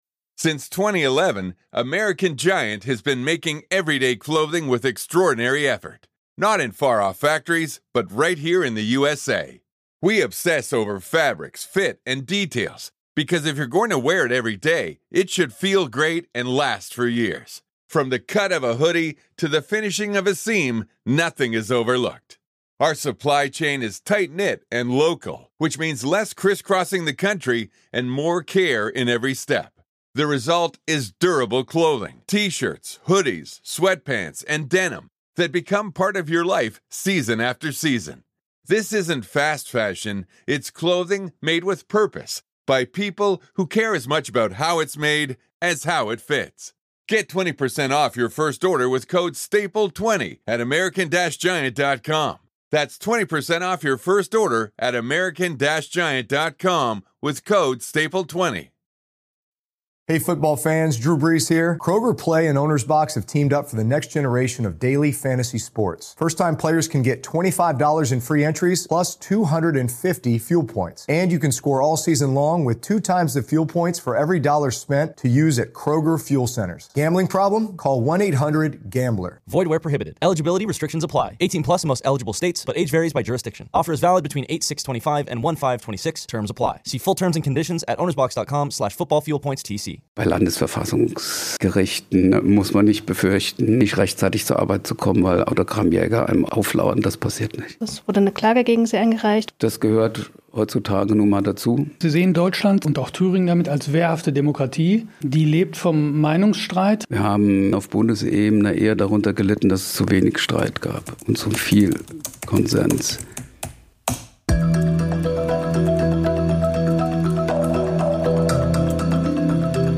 Wie mit Angriffen auf die Unabhängigkeit des Gerichts umgegangen wird und wie sinnvoll Parteiverbote sind, erklärt Klaus von der Weiden, Präsident des Thüringer Verfassungsgerichtshofs, kurz vor dem 30-jährigen Geburtstag des Landesverfassungsgerichts.